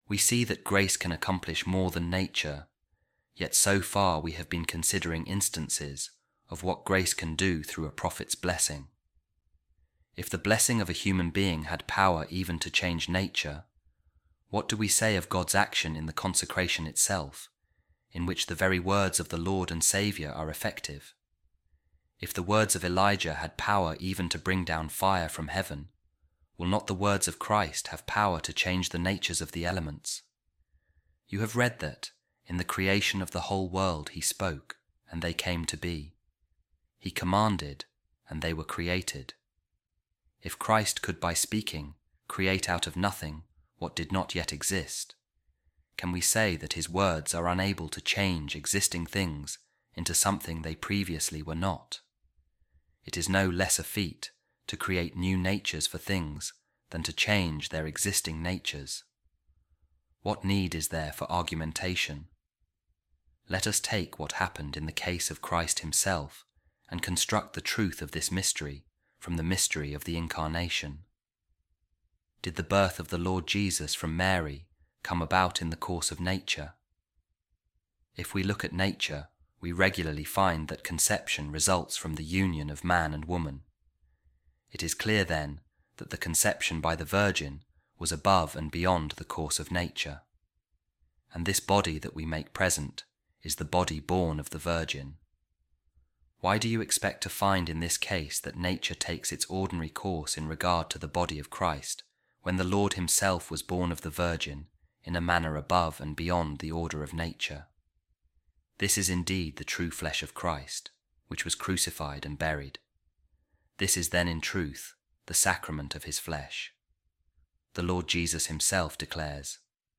Office Of Readings | Week 15, Saturday, Ordinary Time | A Reading From The Treatise Of Saint Ambrose On The Mysteries | The Eucharist | The Word Of Christ